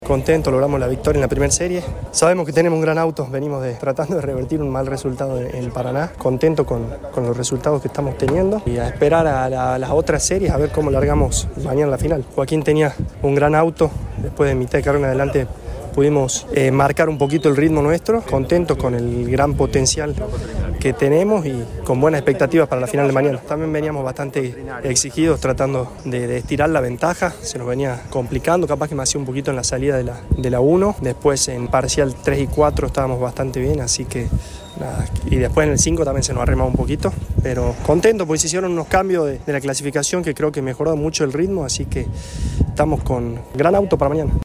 El testimonio del ganador de la primera serie y poleman para la final